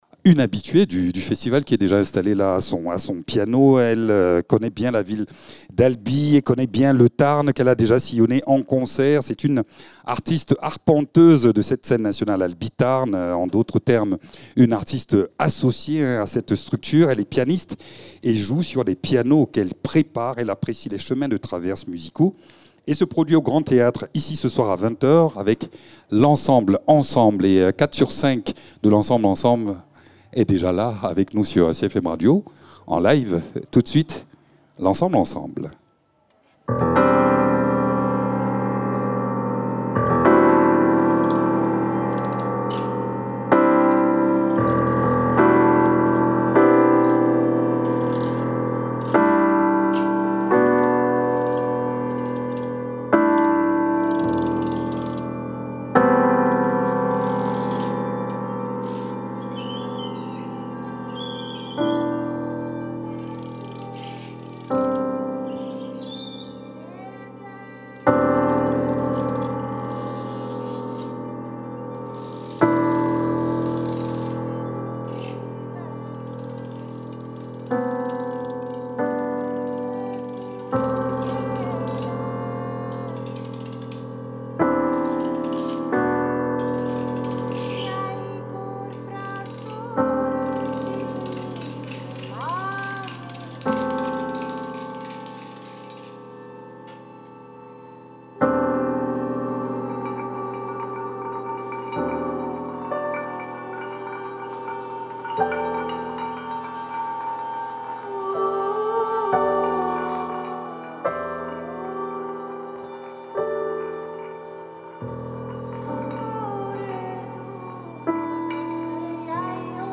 Invité(s) : L’ensemble ensemble et ses cinq artistes
Albi Jazz Festival